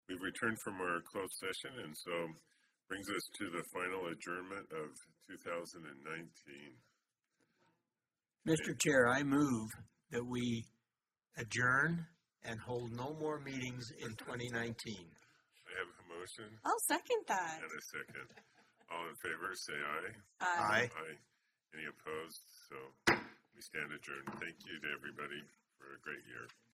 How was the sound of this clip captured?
Regular Meeting of the Board of Trustees of the Utah Transit Authority